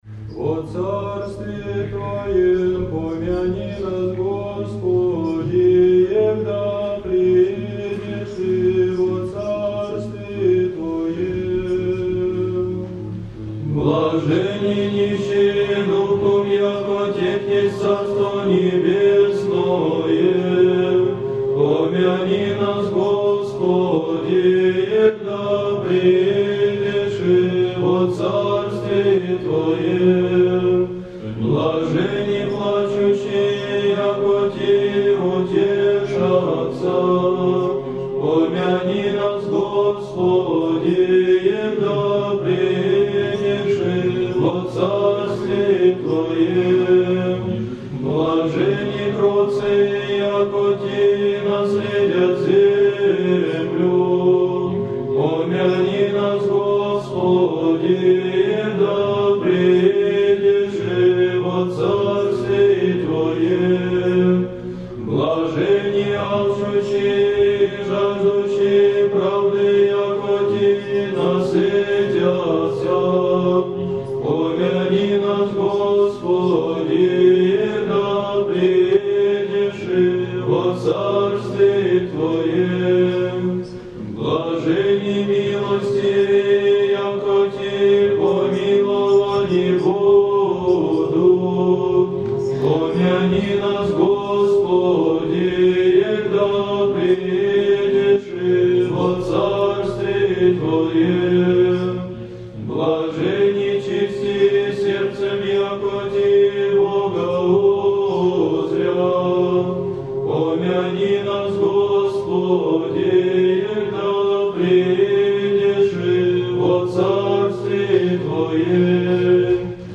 Православная музыка